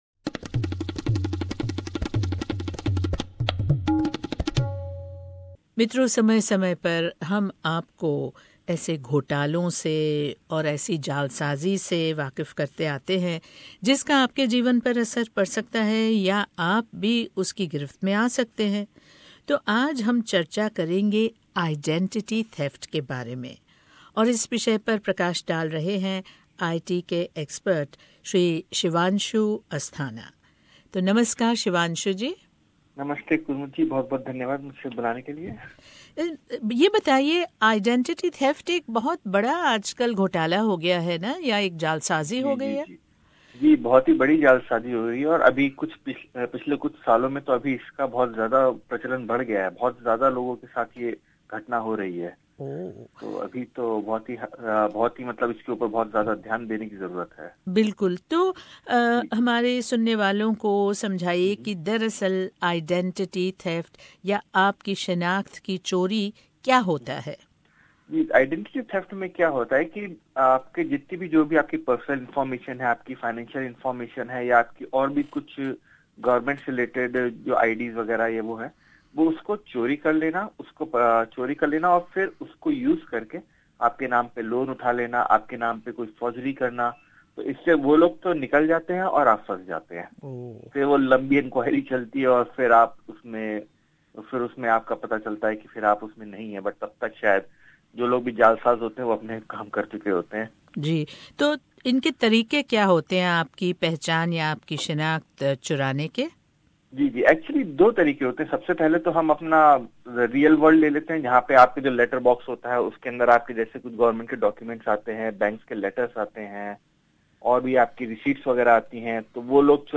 जालसाज़ आपके ख़तों , पर्सनल कंप्यूटर आदि से आपके नाम से घोटाले कर सकते हैं। IT विशेषज्ञ हमें अपना नाम और पहचान सुरक्षित रखने के बारे में कुछ टिप्स दे रहे हैं।